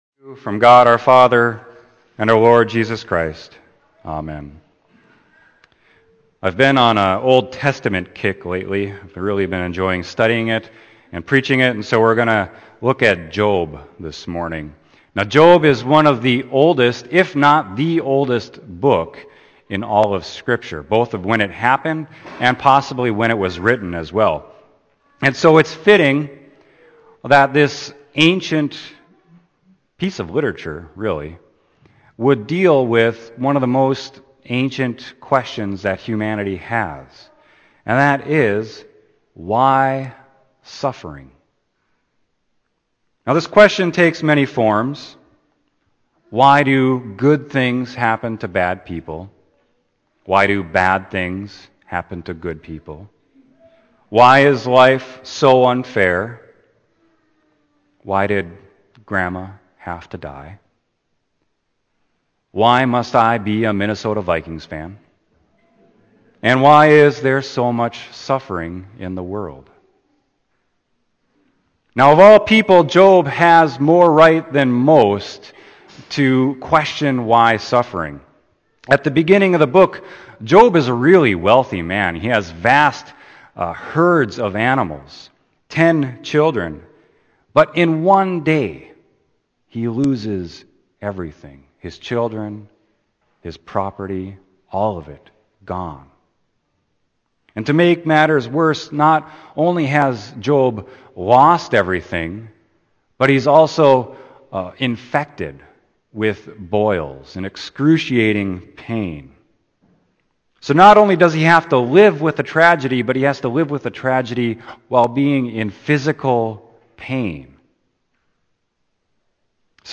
Sermon: Job 38.1-11